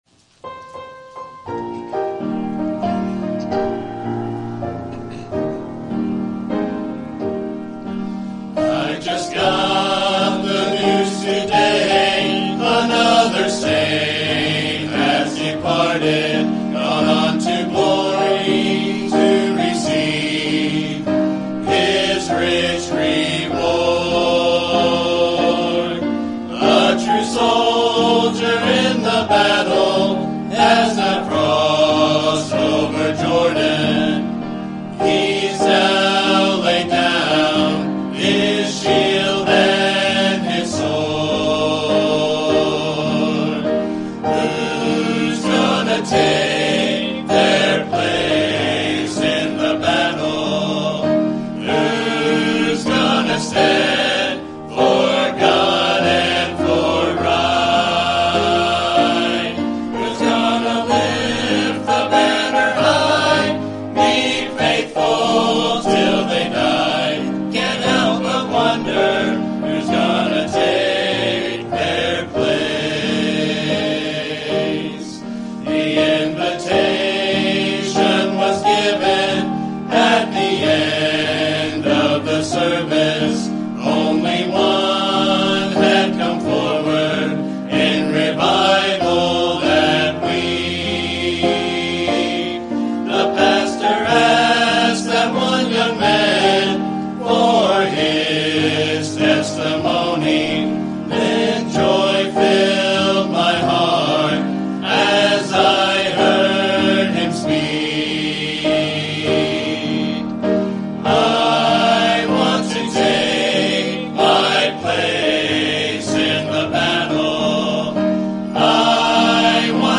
Men's Group